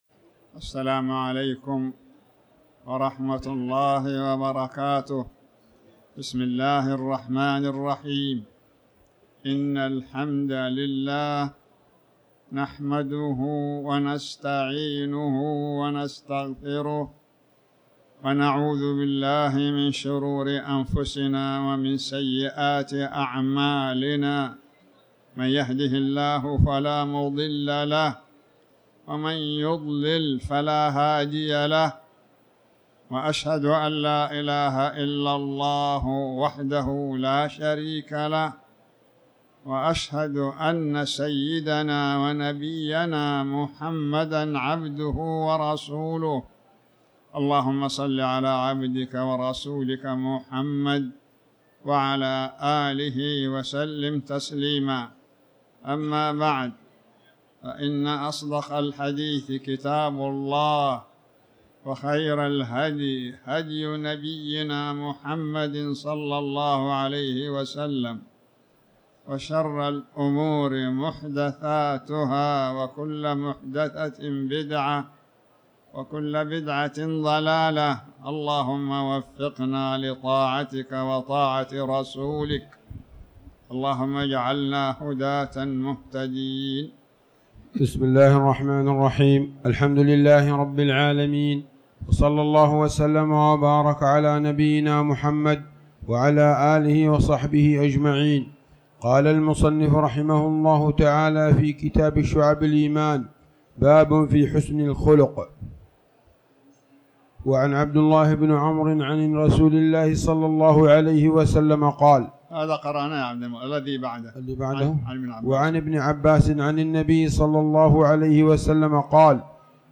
تاريخ النشر ٩ شعبان ١٤٤٠ هـ المكان: المسجد الحرام الشيخ